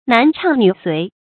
男唱女随 nán chàng nǚ suí
男唱女随发音